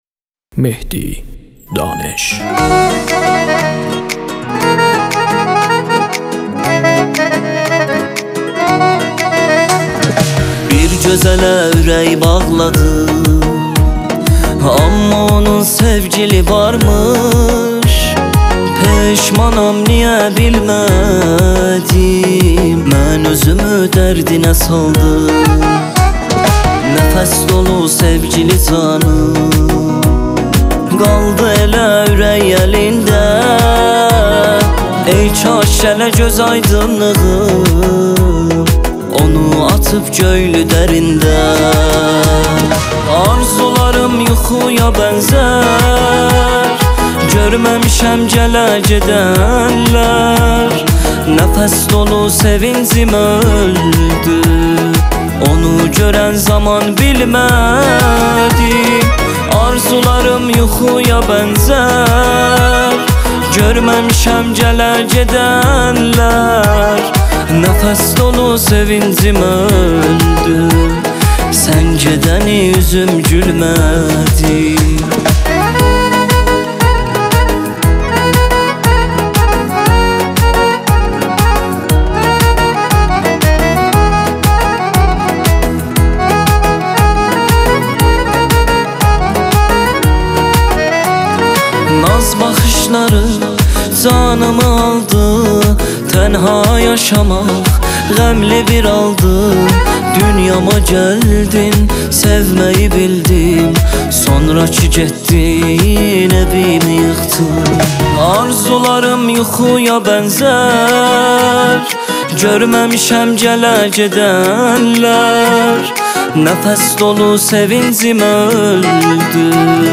پاپ و الکترونیک
عاشقانه